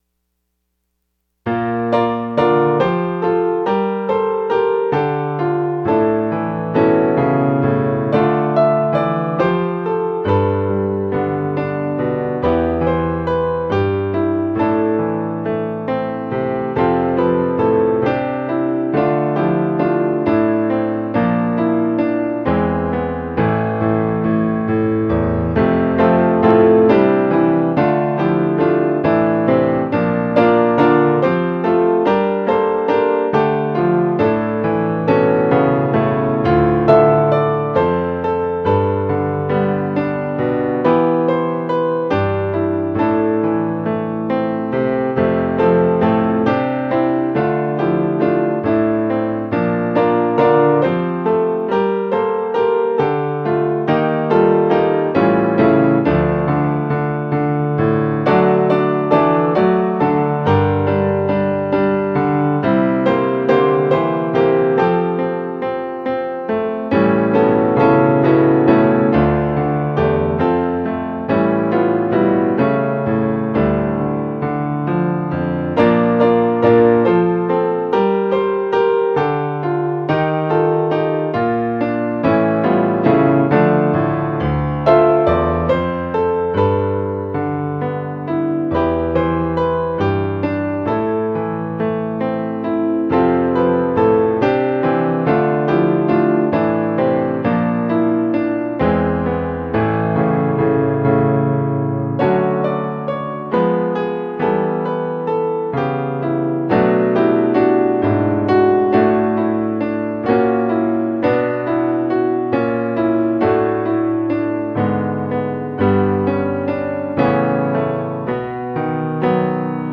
OGG PDF 2025 Piano only recording